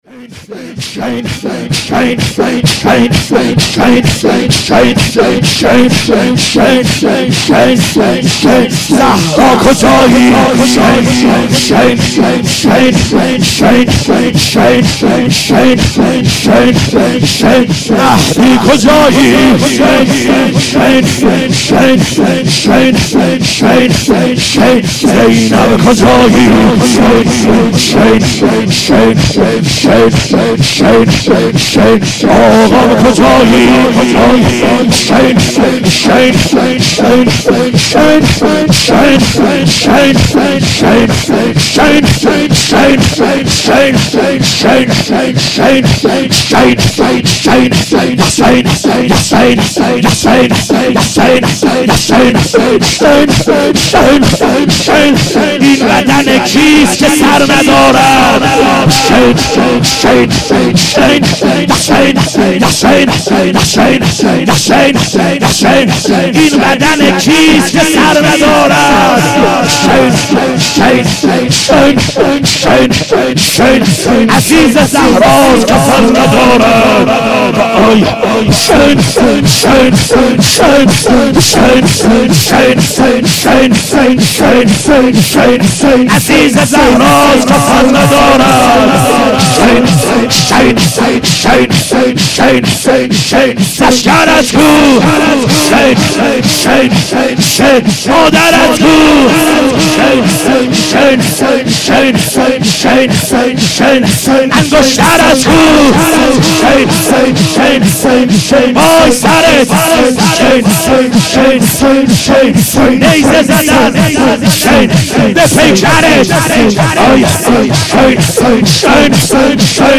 خیمه گاه - بیرق معظم محبین حضرت صاحب الزمان(عج) - لطمه زنی